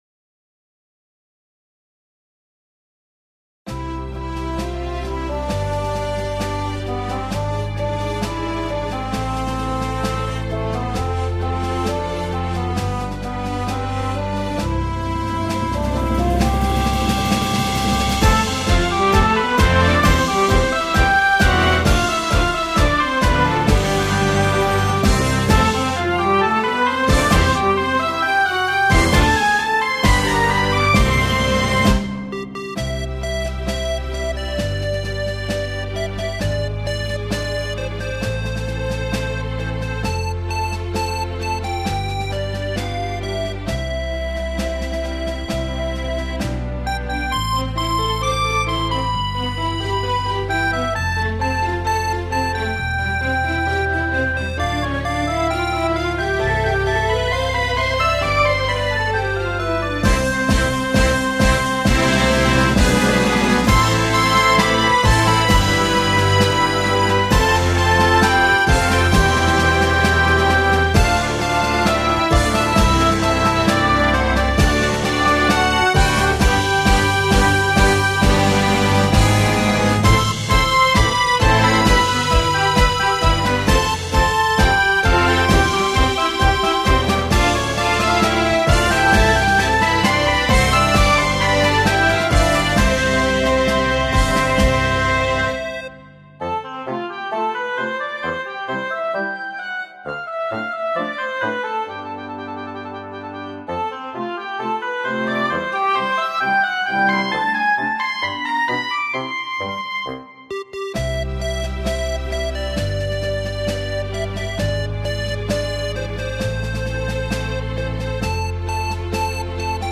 2023-08-05: Thứ Bảy – Thánh Lễ Đại Trào (Dòng Mẹ Chúa Cứu Chuộc, MO) – Ca Đoàn Mân Côi